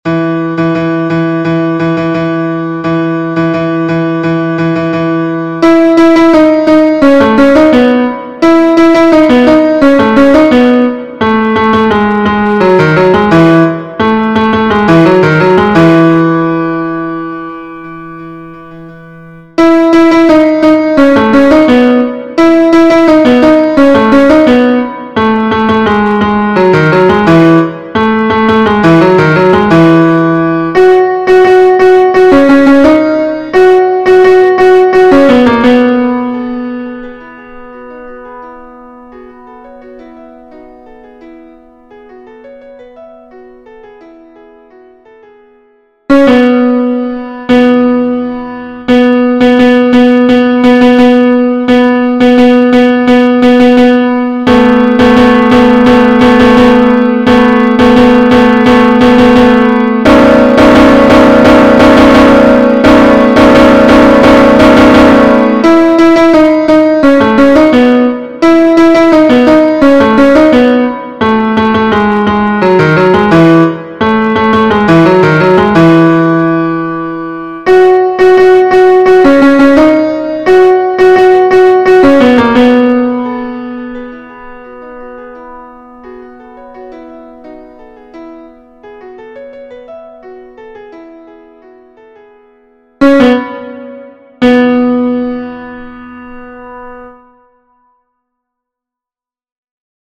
- Chant pour 4 voix mixtes SATB
MP3 versions piano
Tenor Version Piano